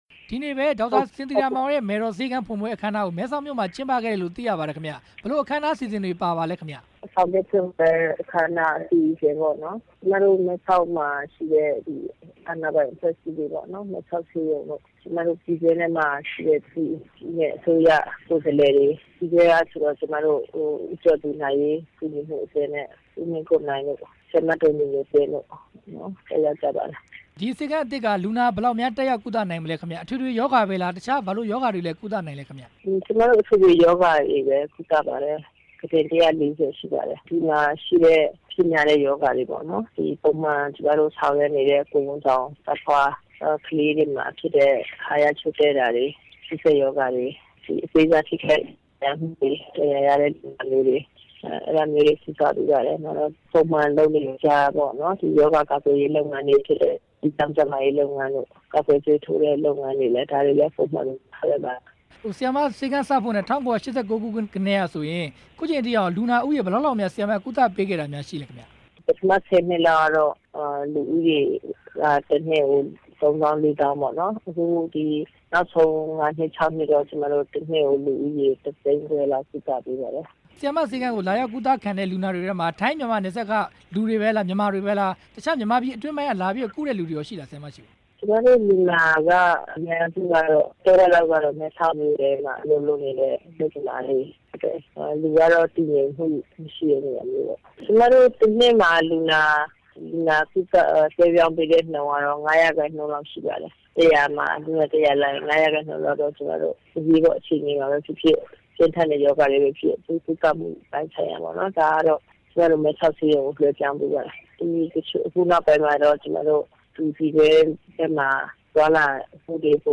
ဒေါက်တာ စင်သီယာမောင် နဲ့ မေးမြန်းချက်